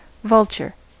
Ääntäminen
US : IPA : [ˈvʌl.tʃɚ]